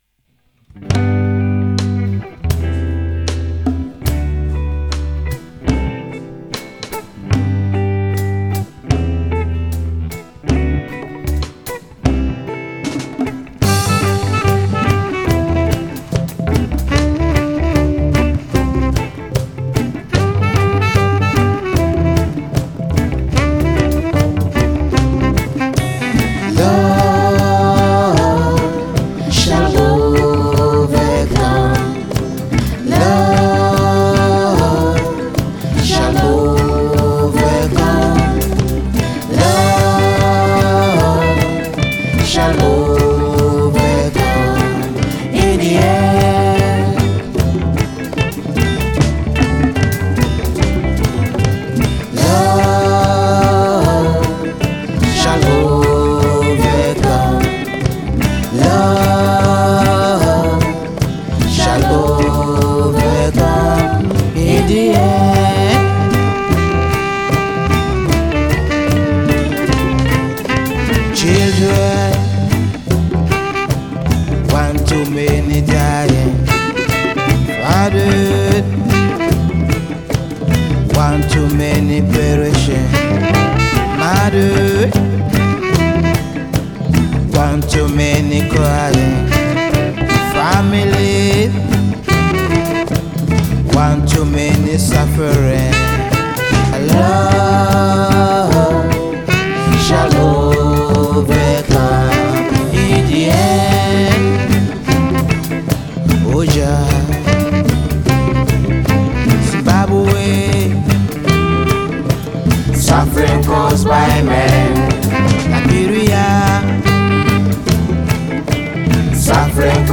Reggae
Keyboard
Sax and Flute
Backing Vocals
Bass